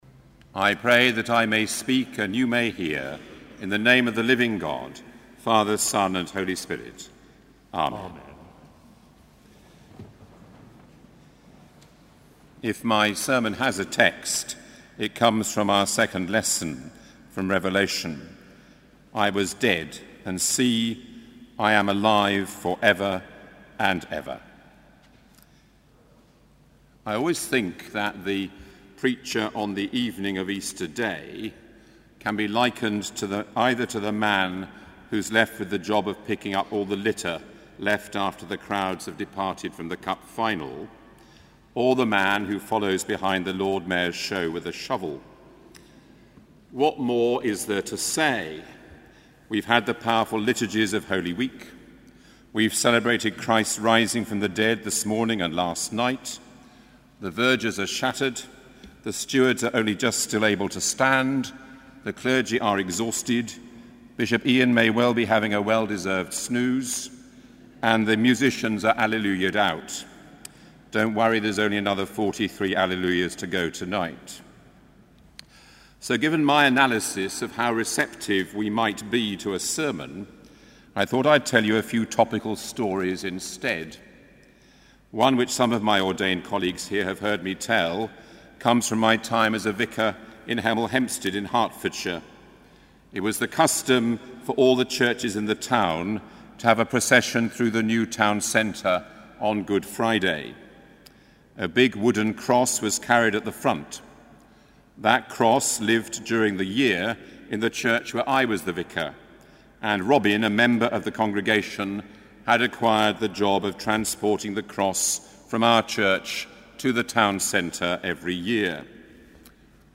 Sermon: Easter Day Evensong 2014